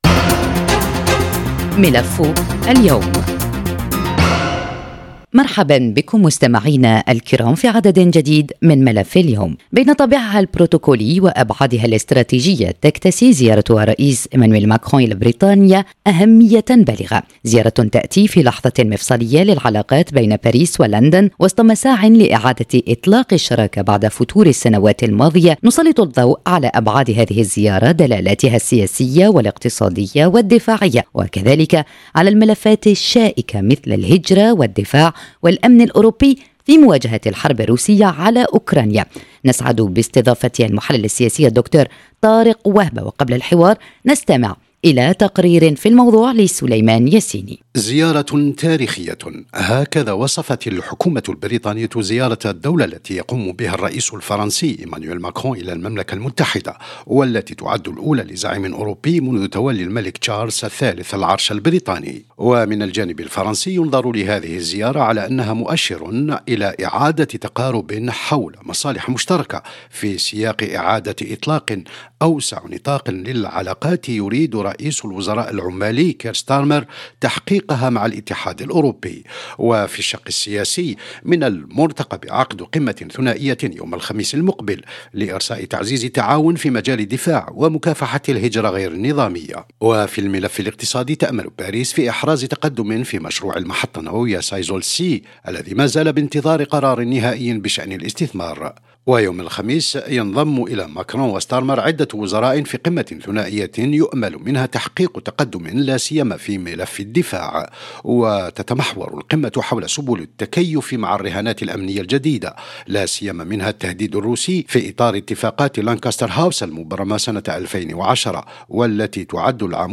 ملف اليوم»، نناقش مع المحلل السياسي